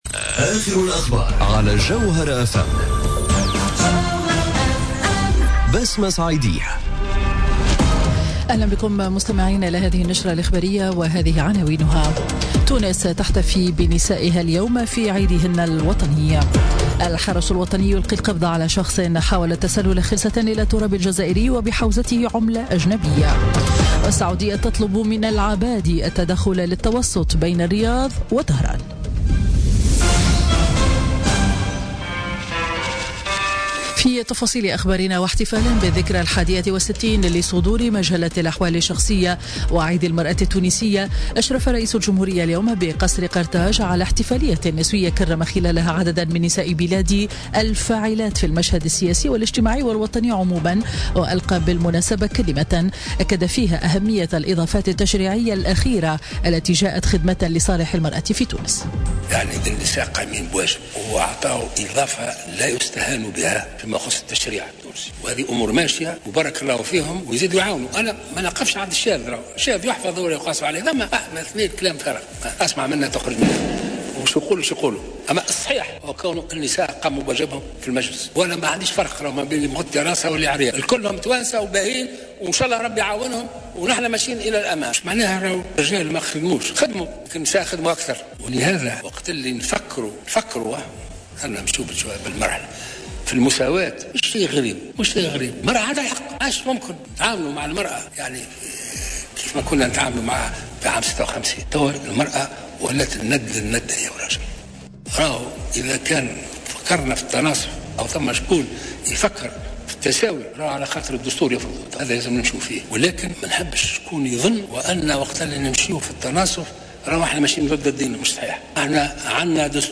نشرة أخبار منتصف النهار ليوم الأحد 13 أوت 2017